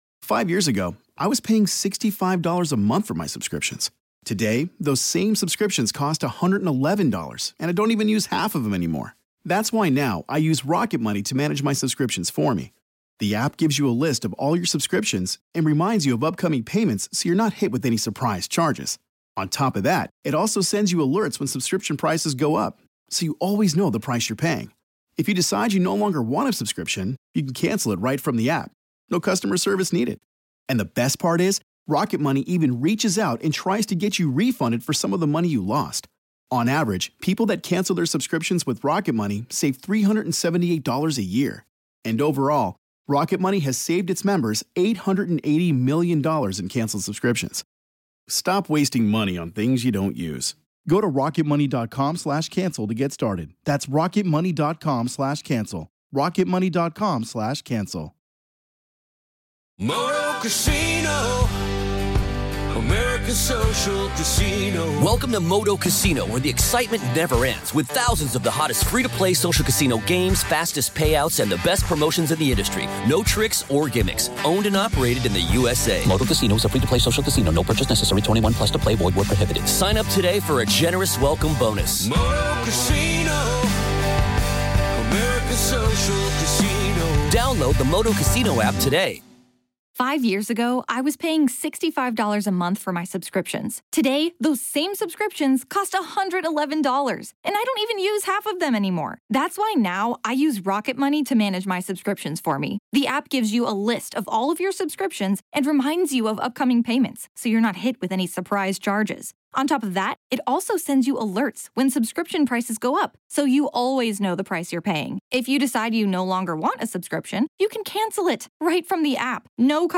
Just how much influence do demons have in our world? What avenues do dark spirits use to influence adults and children? Today we have an in-depth discussion with demonologist